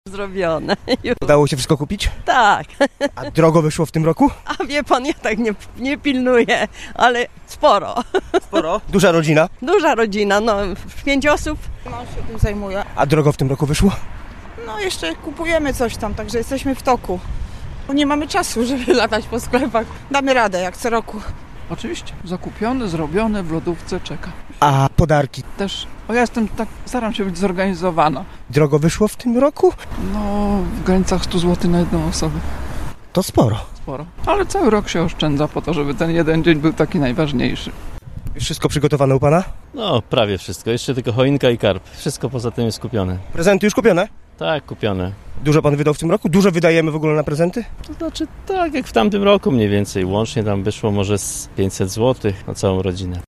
Za kilka dni Wigilia Bożego Narodzenia, a gorzowianie w większości są jeszcze w trakcie przedświątecznych zakupów. O to, jak idą przygotowania do świąt, czy ogarnął nas już szał zakupów i jaką kwotę przeznaczamy na tegoroczne prezenty – pytaliśmy gorzowian: